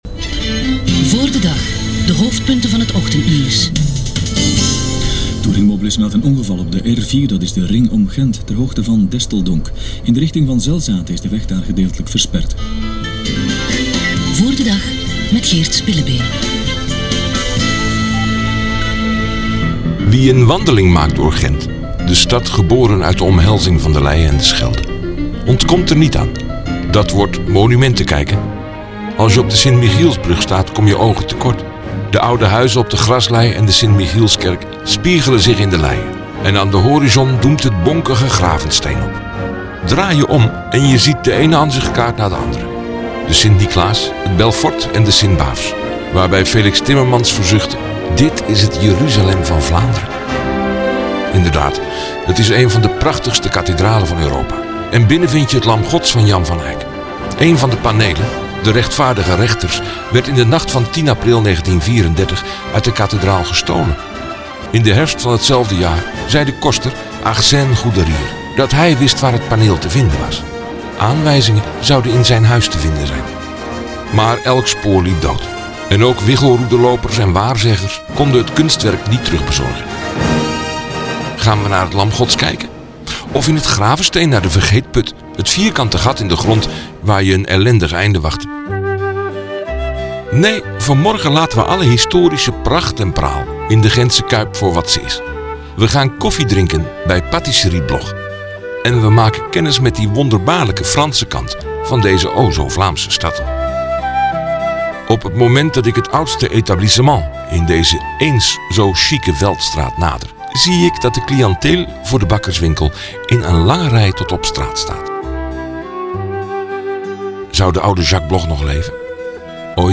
Achter de winkel, het koffiehuis in Elzasser sfeer, planken vloer, houten tafels en stoelen, dressoirkasten langs de muur.
Buiten wachten de winkelstraten, niet de geijkte wandelzones, maar smalle straten waar af en toe nog het schrille geluid van een tram klinkt.